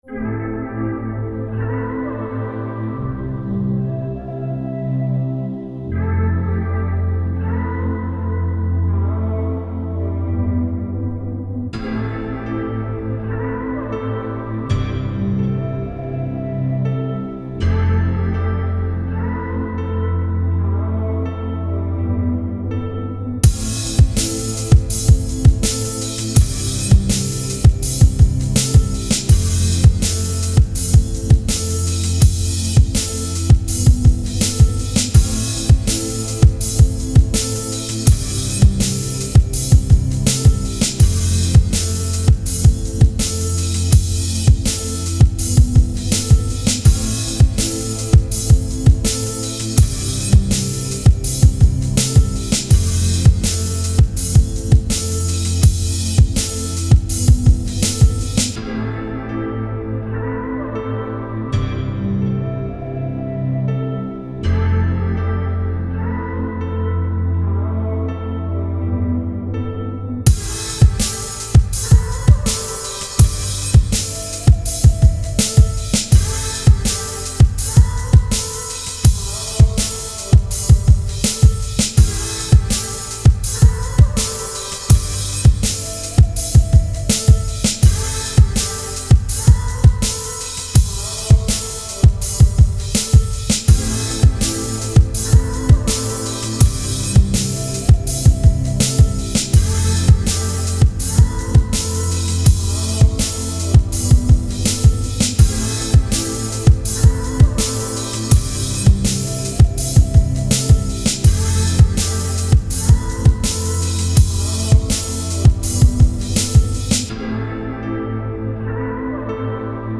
INSTRUMENTAL PRODUCTION